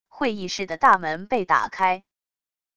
会议室的大门被打开wav音频